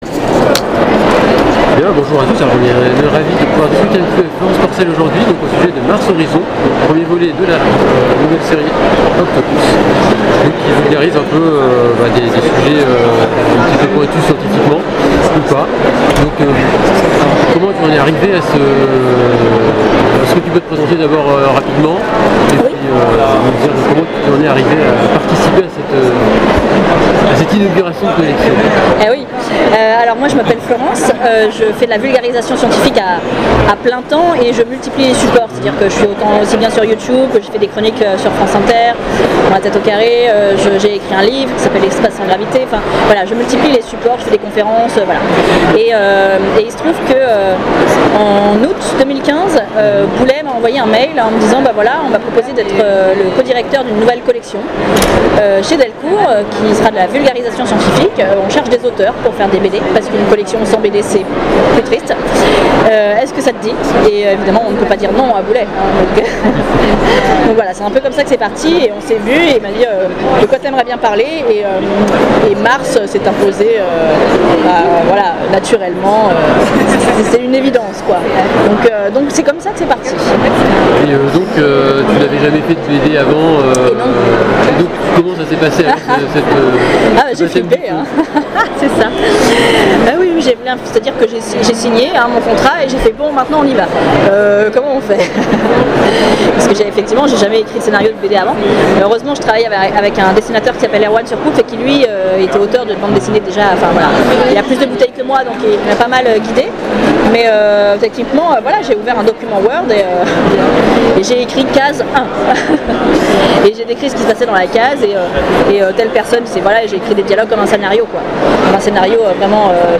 Interview 2017